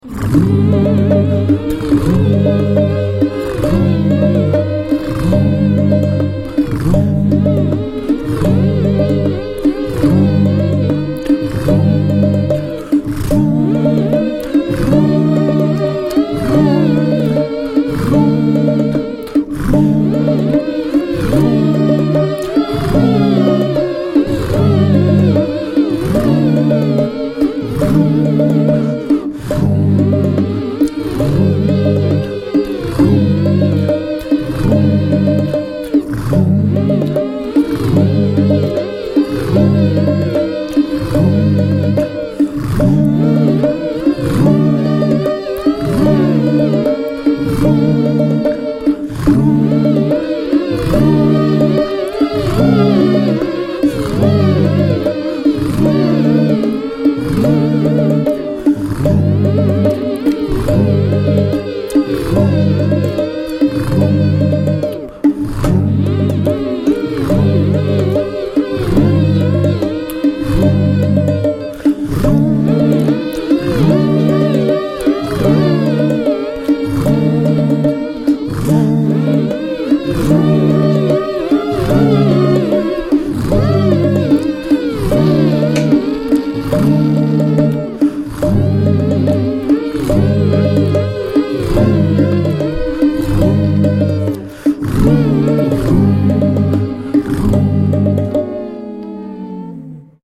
recording them as fast as possible (cuttin' 'em straight to my Mac) for maximum cringe factor! If you give 'em a listen, you may hear the influence of SMiLeY SMiLE's rawness and organic instrumentation, as well as nods to Spike Jones, Bobby McFerrin, and others.
LittleDrummerBoy(AdLibXmas).mp3